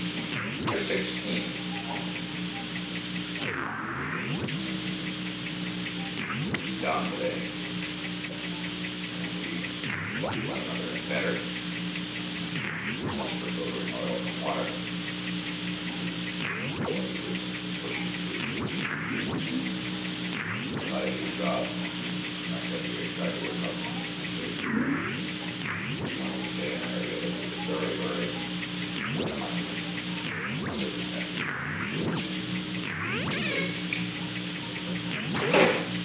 Recording Device: Old Executive Office Building
The Old Executive Office Building taping system captured this recording, which is known as Conversation 295-009 of the White House Tapes.
The President dictated a memorandum for the file.